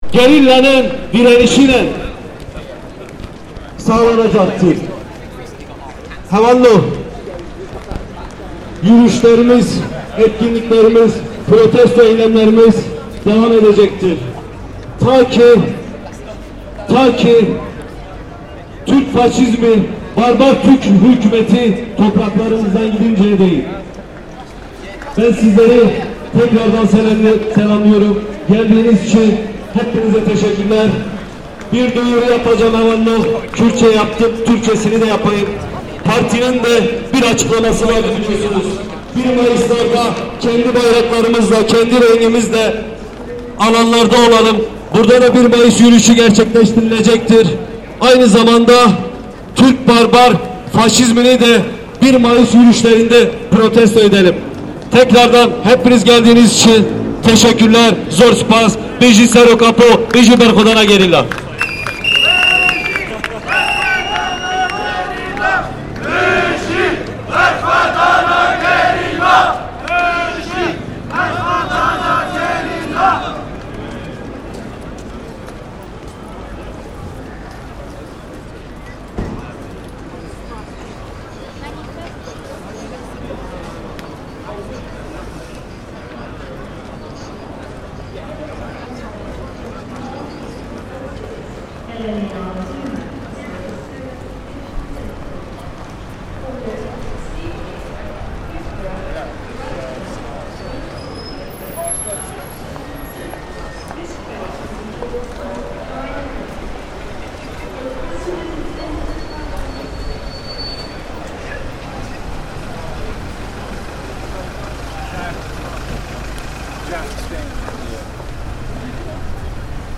A pro-Kurdistan protest recorded outside King's Cross station in London, 2022.